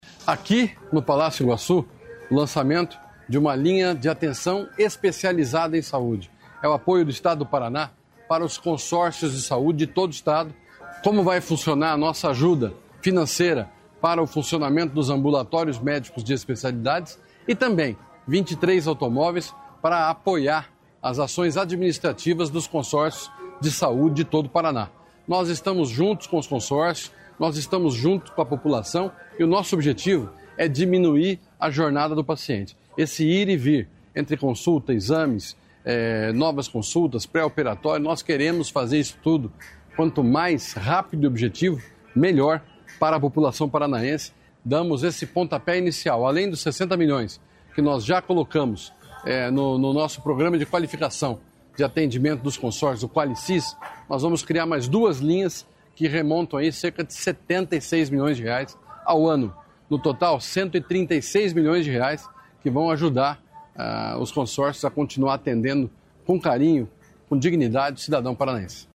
Sonora do secretário de Saúde, Beto Preto, sobre aumentar atendimentos dos consórcios